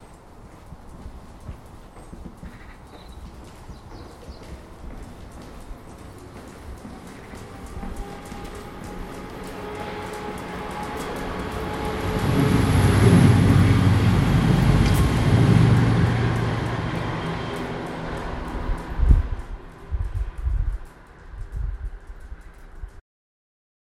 In a tunnel, train passing
Waiting for the sound of a passing train in a tunnel in Bicester, Oxfordshire.